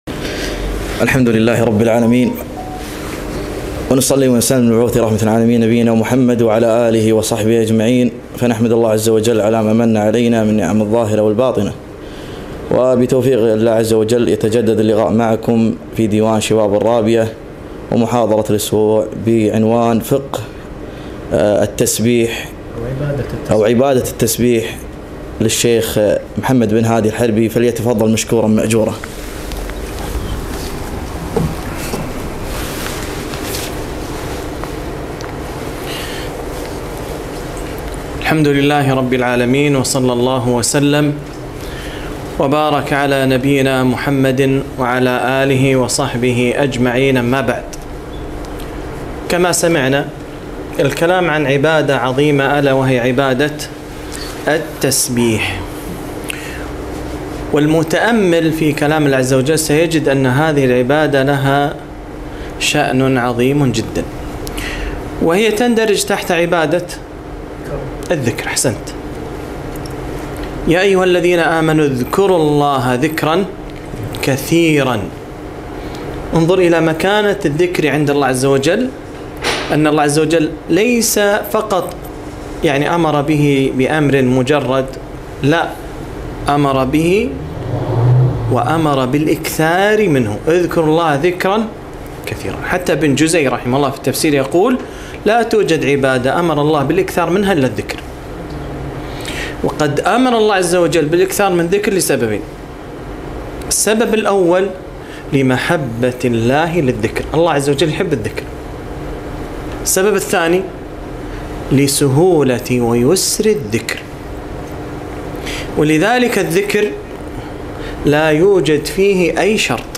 محاضرة - عبادة التسبيح